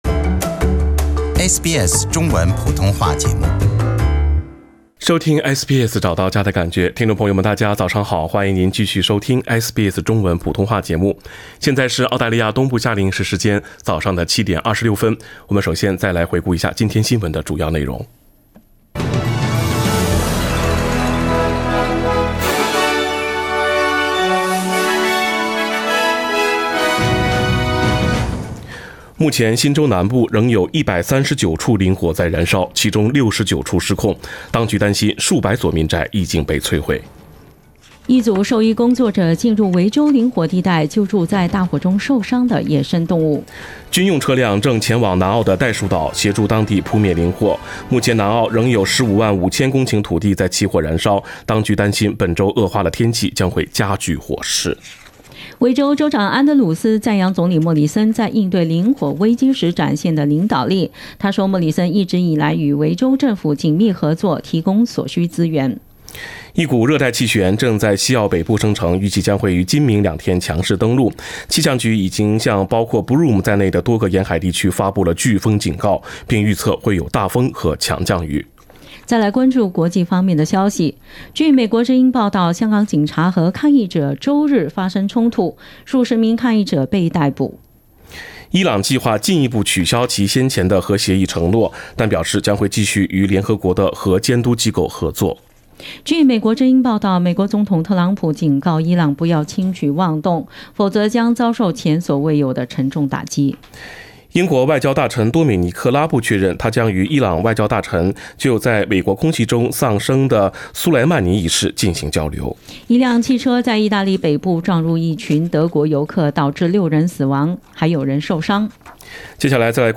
sbs早新闻(1月6日)